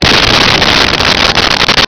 Sfx Pod Flamethrower On
sfx_pod_flamethrower_on.wav